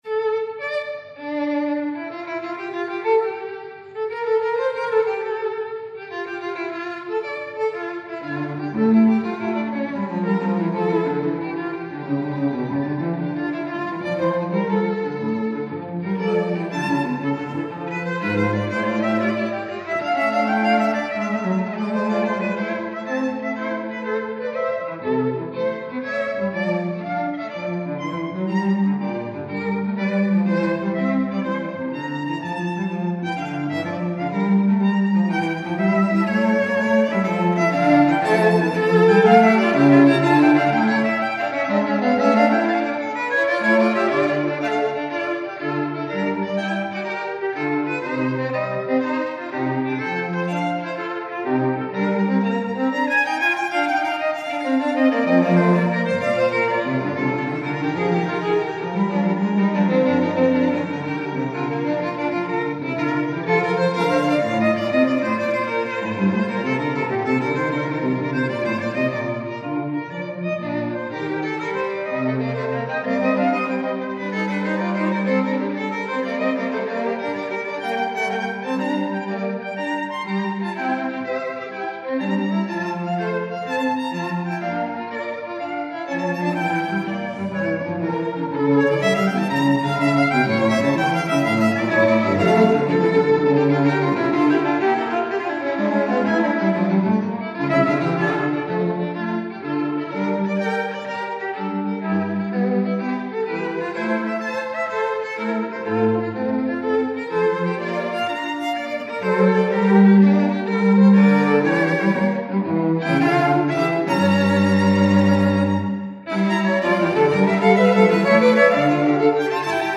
The Thuringian Chamber Orchestra,
Guest Conductor: George Malcolm.
Contrapunctus XVII Rectus– Variant of theme and its inversion
Our performance is given by a chamber orchestra; we feel that this instrumentation offers the listener the two most important factors: clarity of structure, and enjoyability.
When listening to the different fugues one can follow clearly the contrapuntal patterns; yet at the same time one is moved by the sheer beauty of the music itself.